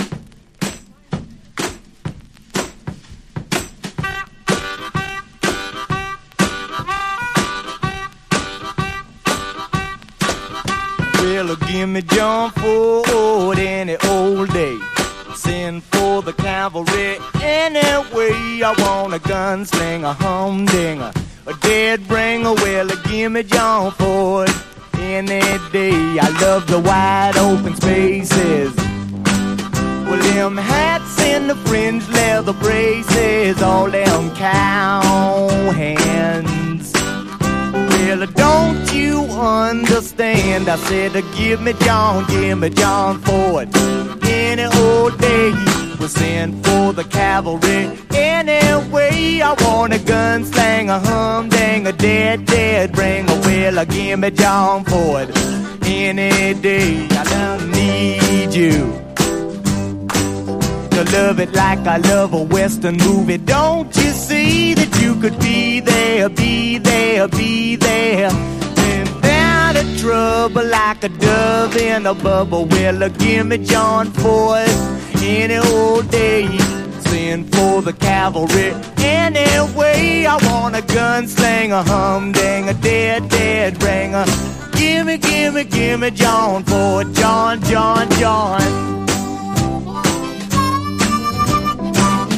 陽だまりメロウ・フォークS.S.W.必携盤！